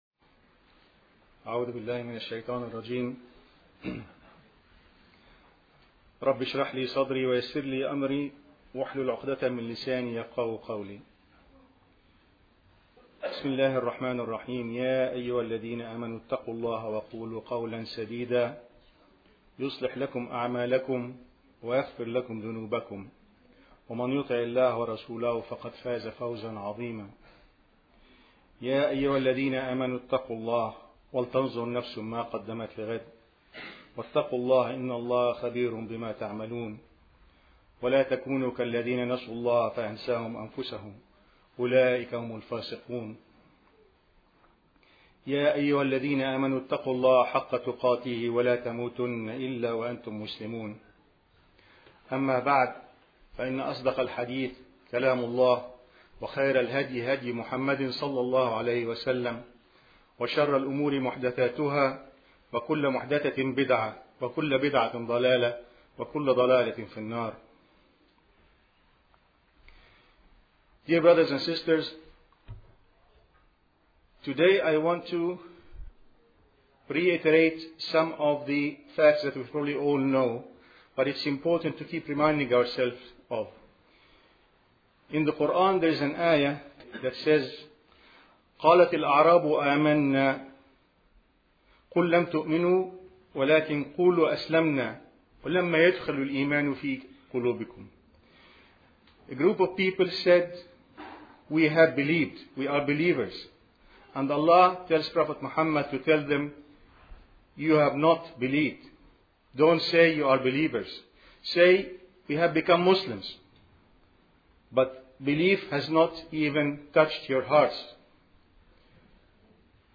To hear this audio khutbah, please click here: HE Ihsan – Excellence